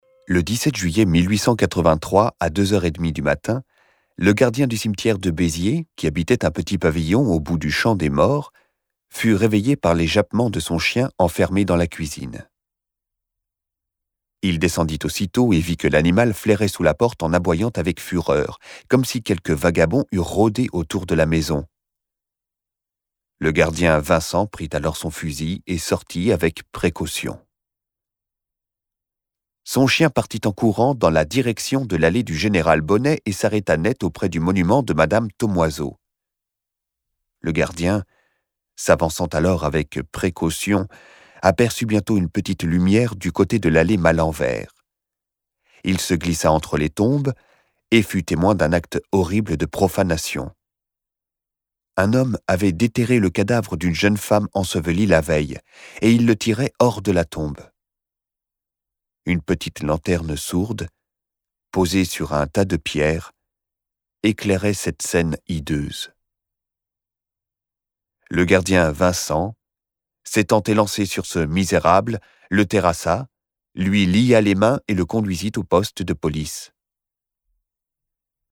Extrait gratuit - La tombe de Guy Maupassant de